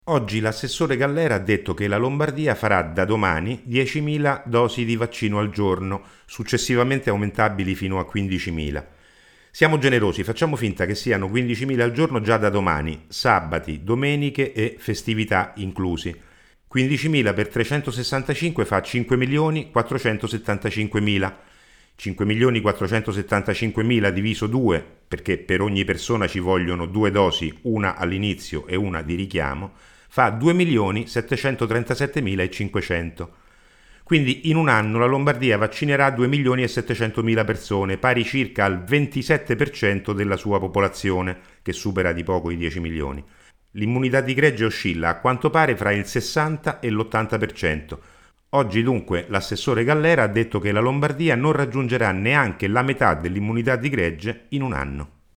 Per rispondere alle critiche, l’assessore Gallera oggi ha detto che da domani la Lombardia farà 10mila dosi di vaccino al giorno, che poi – non si sa bene quando – diventeranno 15 mila al giorno. Il fact checking su questa frase di Gallera a cura di Alessandro Capriccioli, consigliere regionale radicale nel Lazio che segue i numeri dell’epidemia dall’inizio ed è autore anche di un podcast intitolato Droplet: